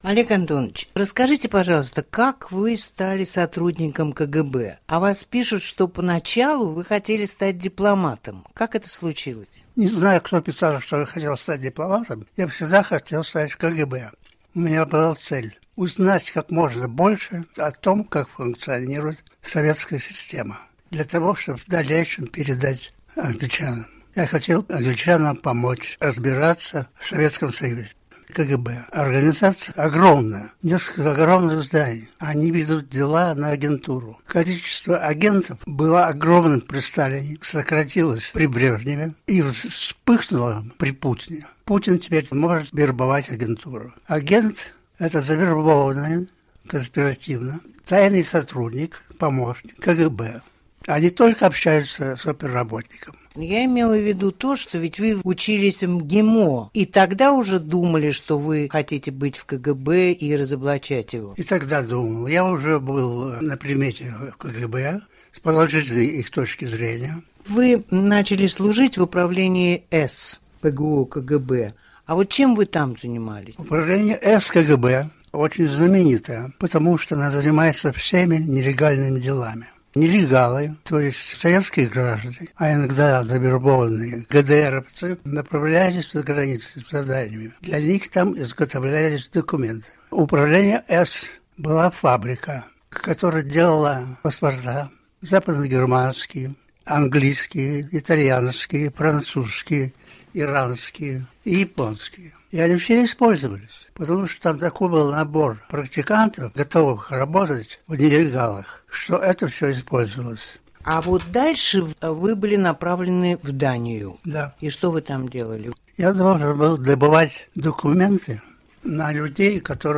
Олег Гордиевский - о своем побеге из СССР. Полная версия интервью